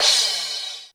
Index of /90_sSampleCDs/300 Drum Machines/Korg DSS-1/Drums01/03
Crash.wav